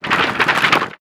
Shake_v2_wav.wav